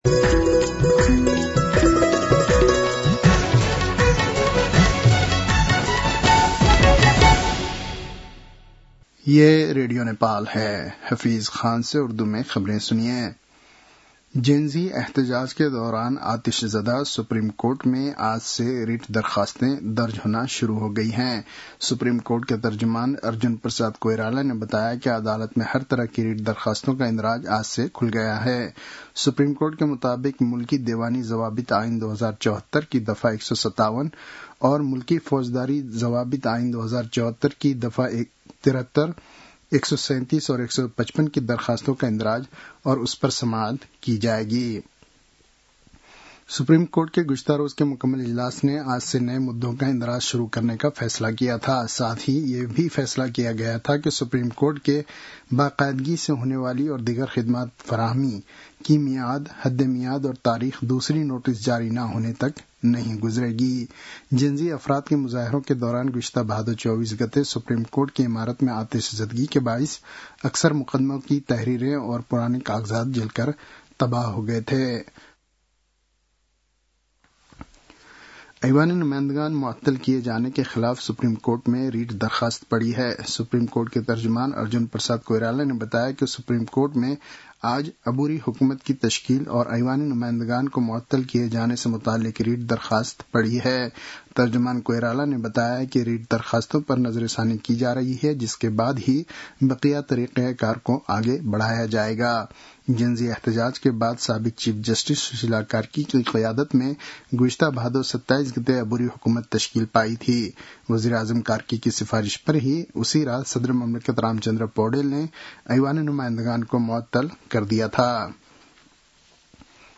उर्दु भाषामा समाचार : २८ असोज , २०८२